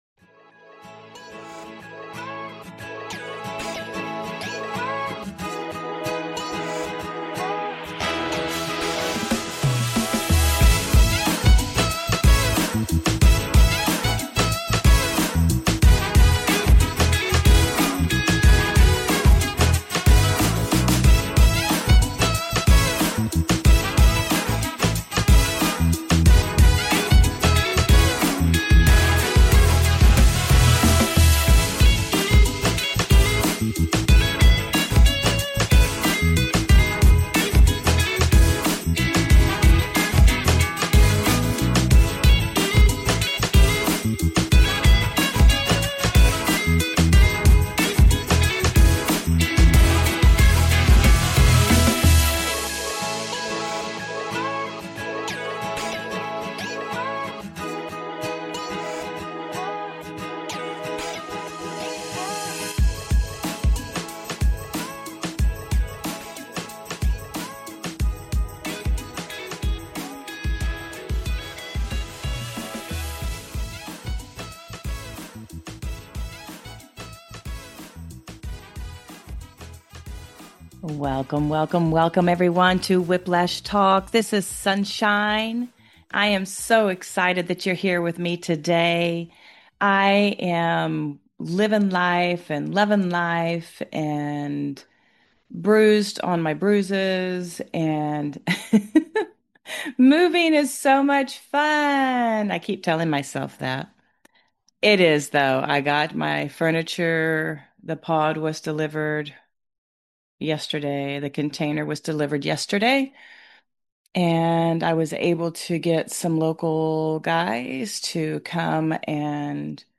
Weekly Show